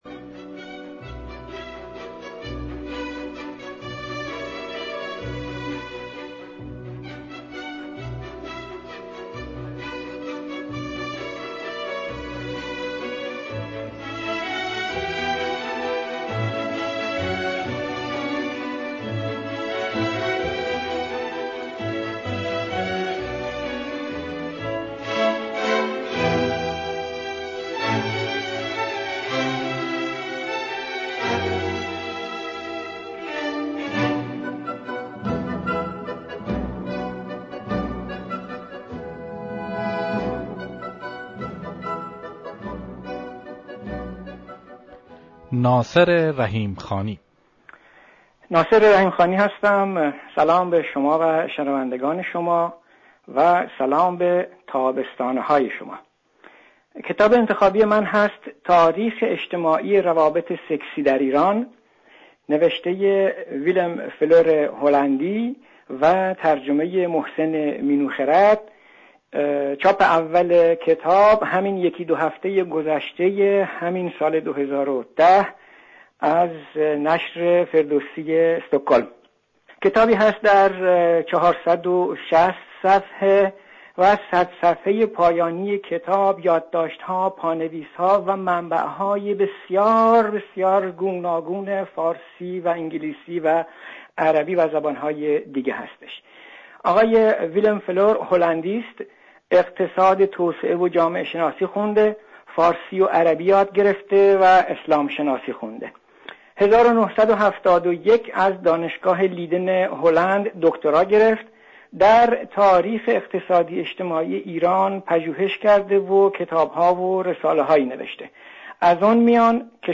در این برنامه ،گویندگان به انتخاب خود کتابی را برای معرفی برگزیدند و در پایان معرفی کتاب، ترانه ای باز به انتخاب خود پیشکش شنوندگان کردند